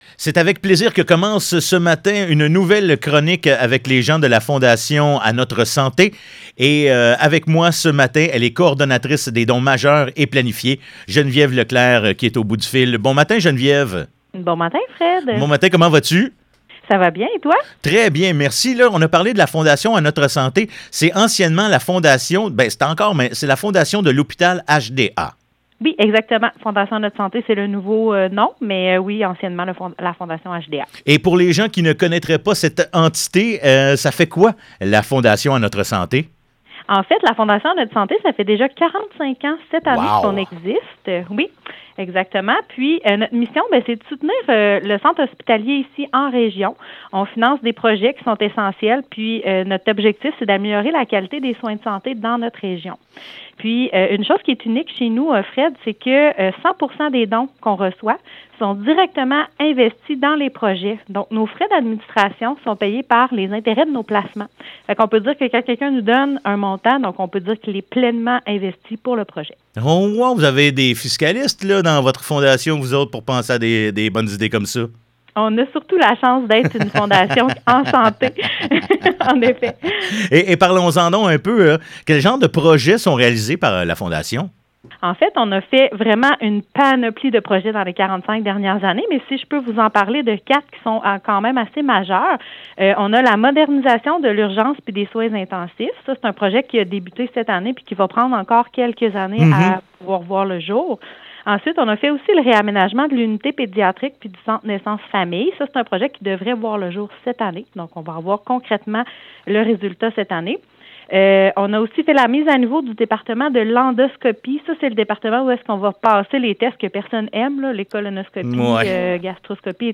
Chronique radio du 5 février